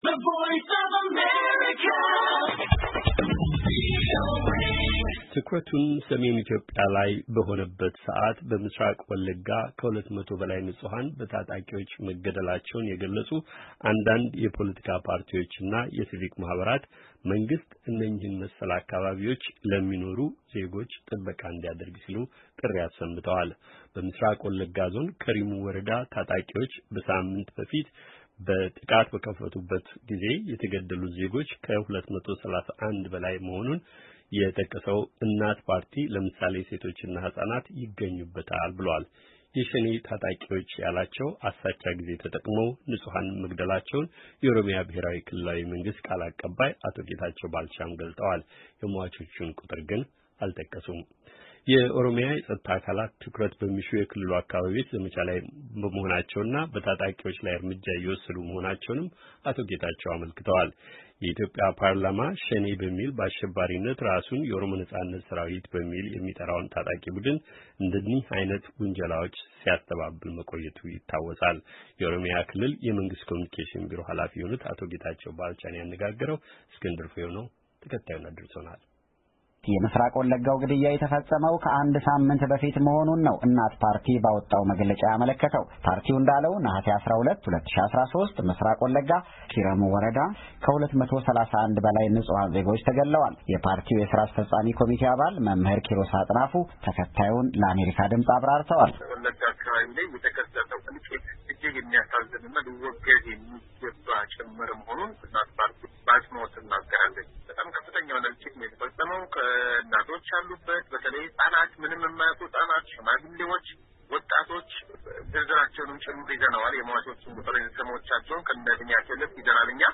የኦሮምያ ብሔራዊ ክልላዊ መንግሥት የኮምዩኒኬሽን ቢሮ ሃላፊ የሆኑትን አቶ ጌታቸው ባልቻን አነጋግረናል።